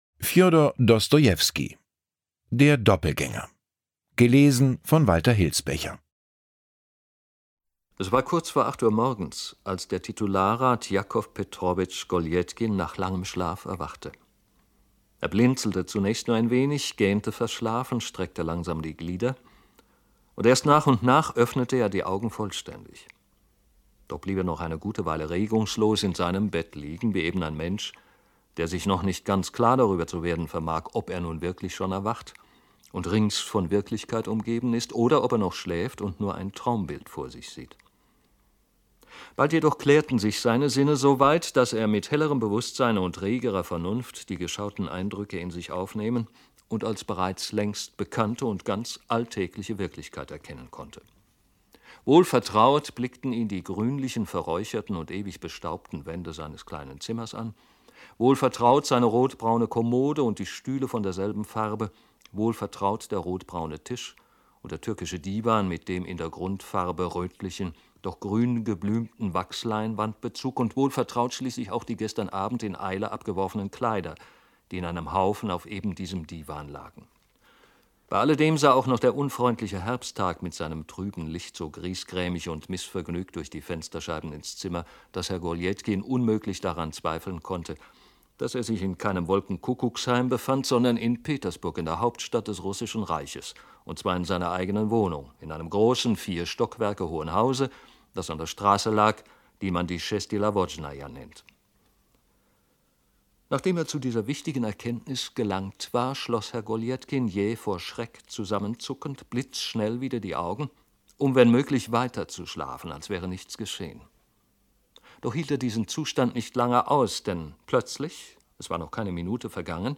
Hörbuch; Literaturlesung